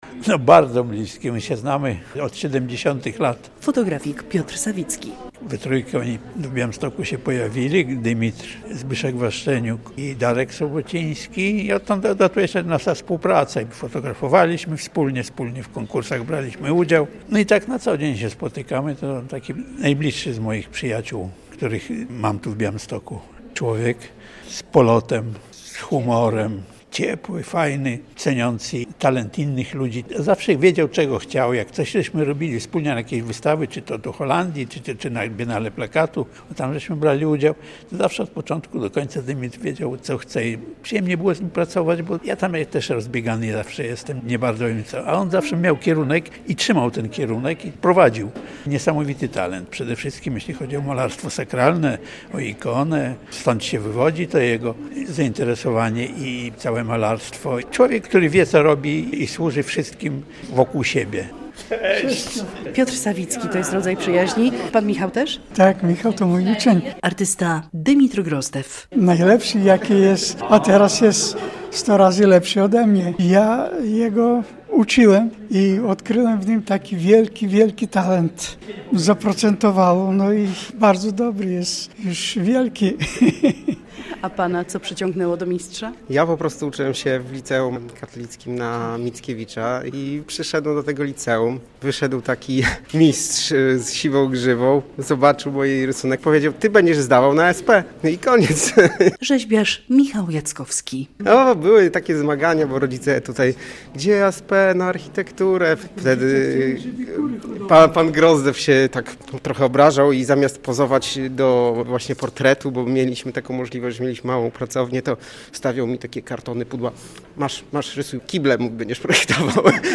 Wernisaż wystawy
relacja